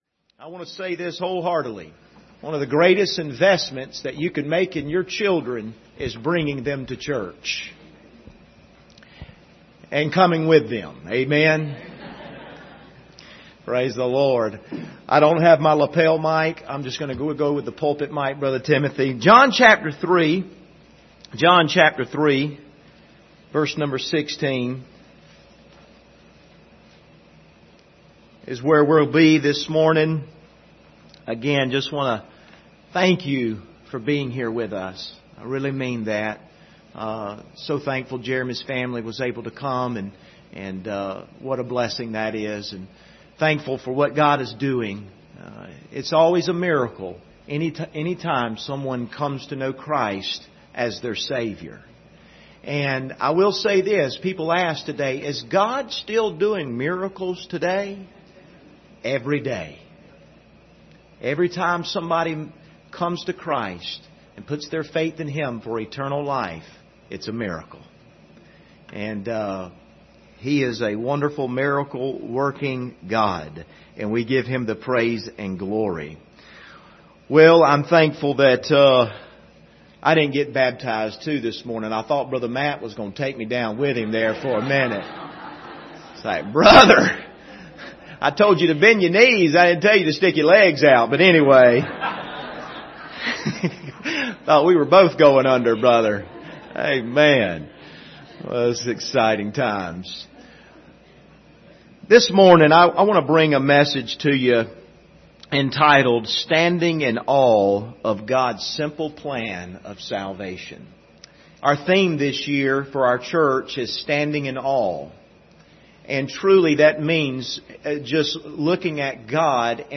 Service Type: Sunday Morning Topics: God's love , salvation